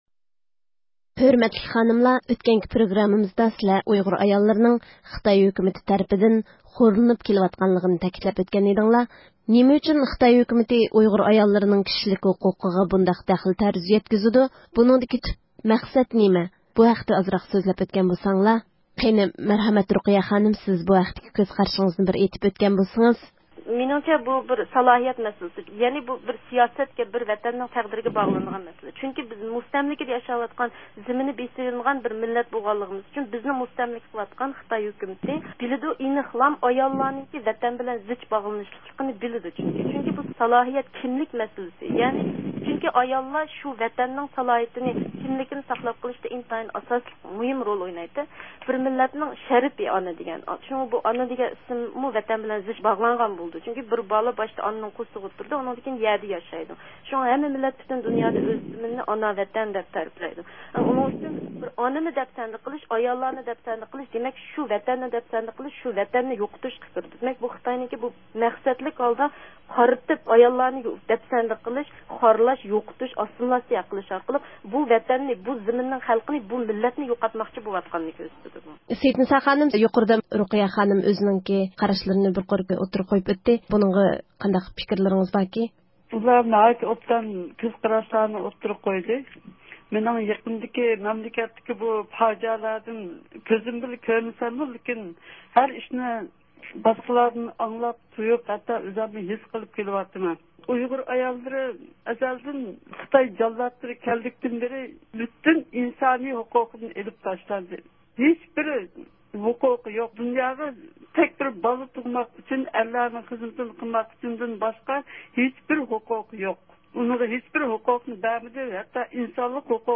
كىشىلىك ھوقۇق ھىمايىچىسى، 2007 - يىللىق » نوبىل » تىنچلىق مۇكاپاتىنىڭ كۈچلۈك نامزاتى بولۇپ تونۇلغان رابىيە خانىم زىيارىتىمىزنى قوبۇل قىلىپ، ئاياللارغا تىلەكلىرىنى بىلدۈردى.